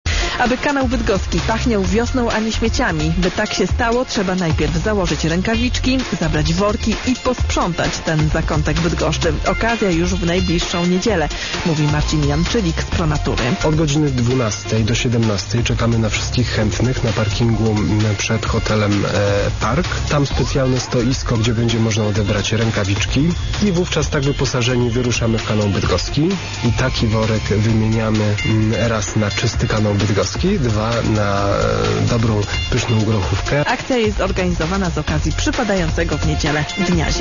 Pobierz plik (radio_eska_bydgoszcz-2012_04_20_13_05_22-wiadomosci.wav)radio_eska_bydgoszcz-2012_04_20_13_05_22-wiadomosci.wav[ ]0 kB